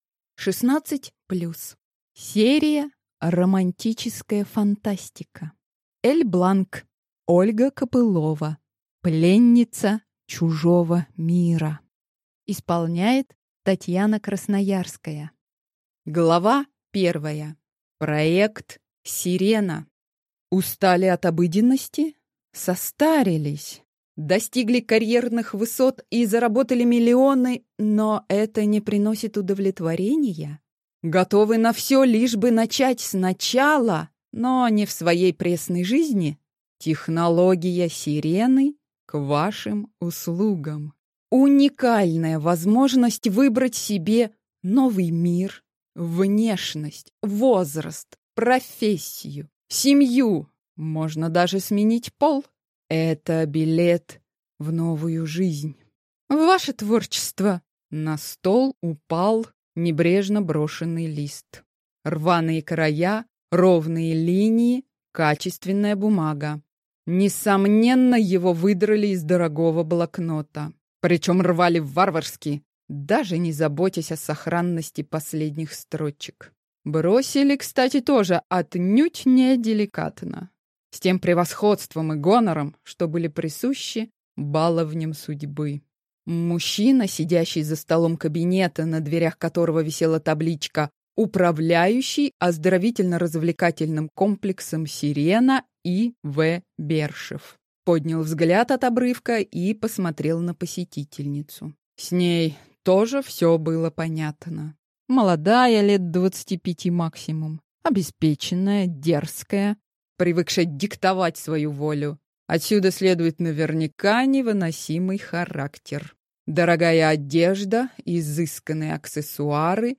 Аудиокнига Пленница чужого мира | Библиотека аудиокниг